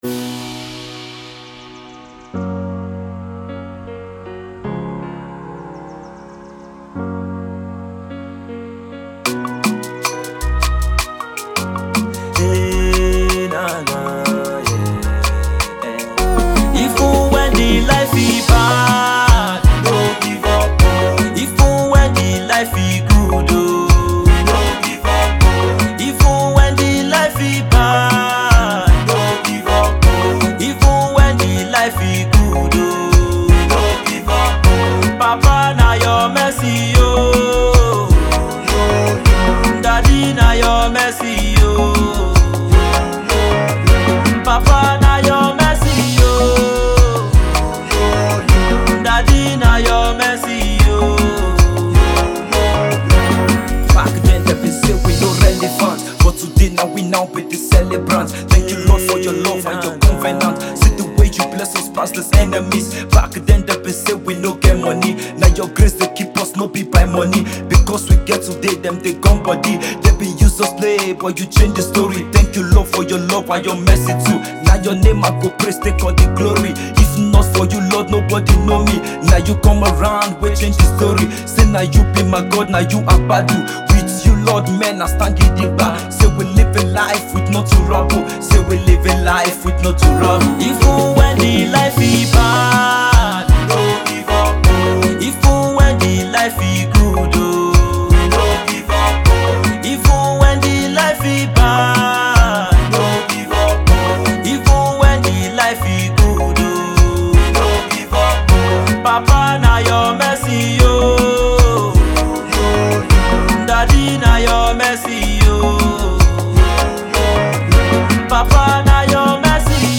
inspirational songs